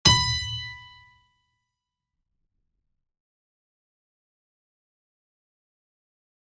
piano-sounds-dev
HardAndToughPiano